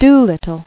[do-lit·tle]